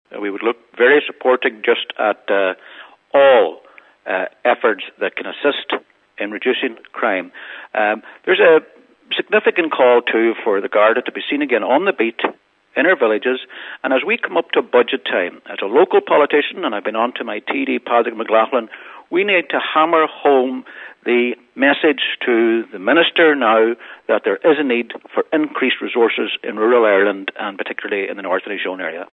Cllr Albert Doherty, who this week renewed calls for an urgent meeting with the Garda Superintendent in Inishowen, says efforts to tackle crime must be intensified………